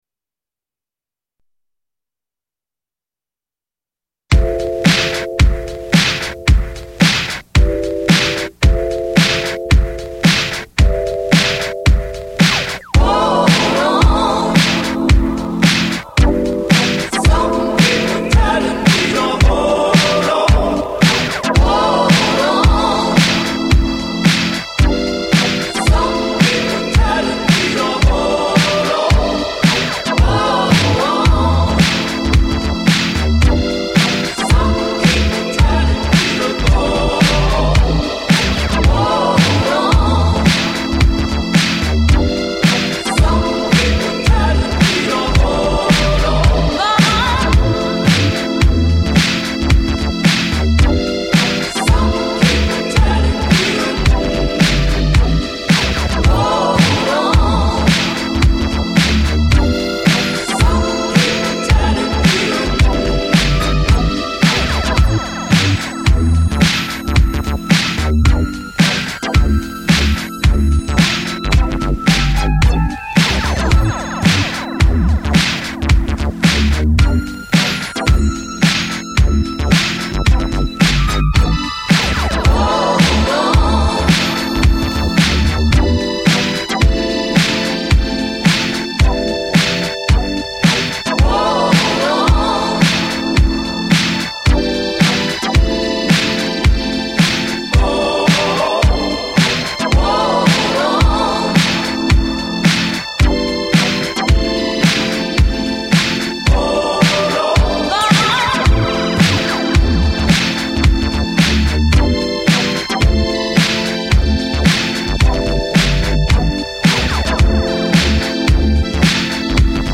※試聴はダイジェストです。